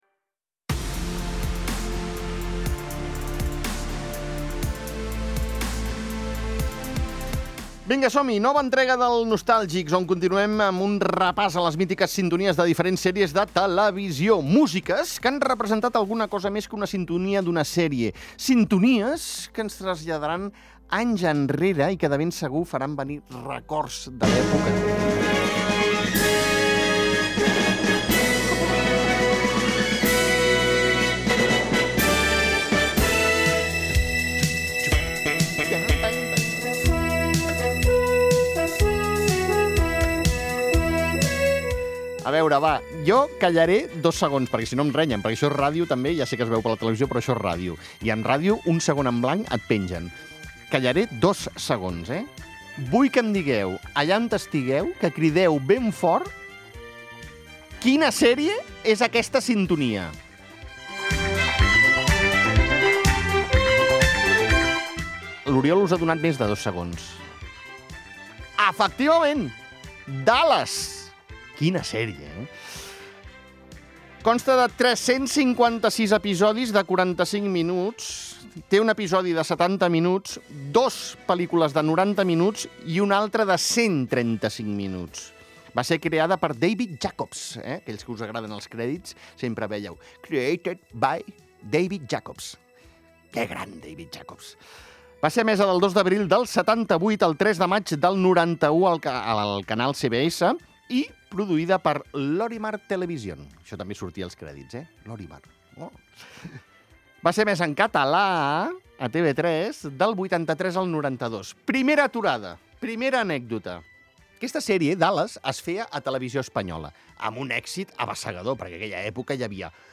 mítiques sintonies de diferents sèries de televisió